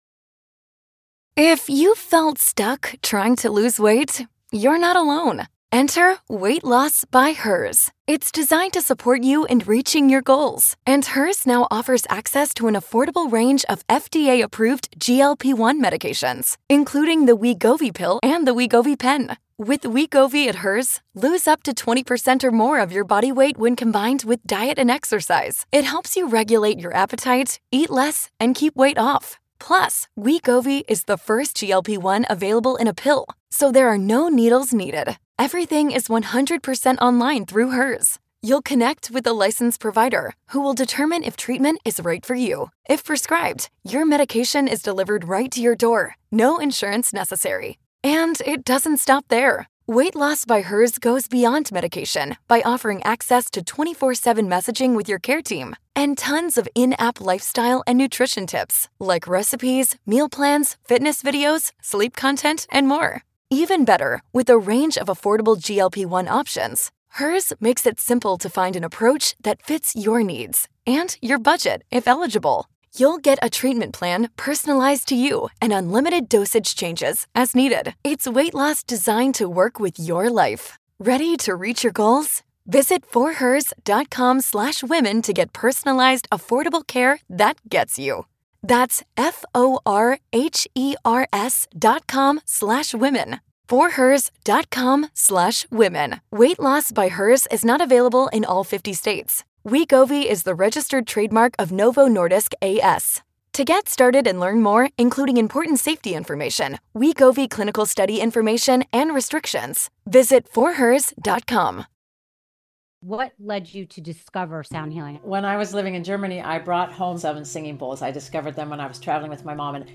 Don’t miss this inspiring and insightful conversation!